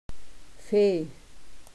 Wörterbuch der Webenheimer Mundart